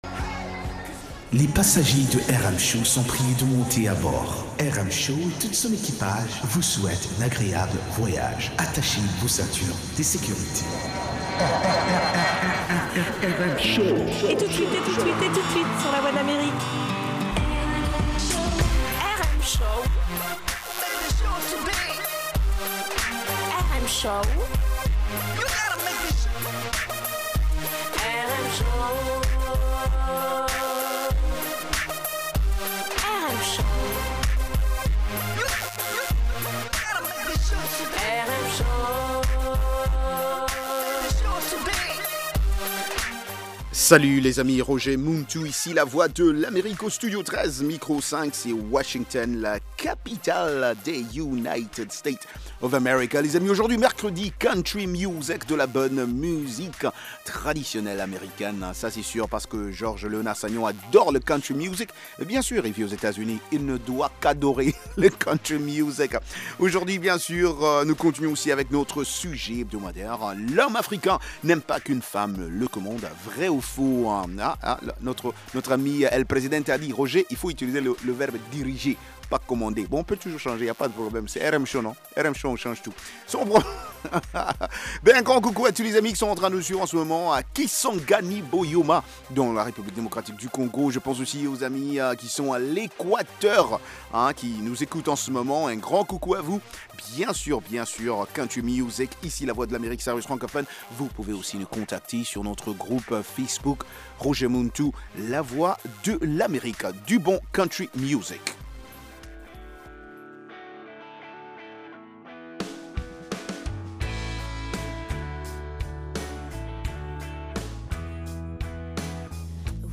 Balade musicale dans le monde entier, rions un peu avec de la comédie, interviews des divers artistes avec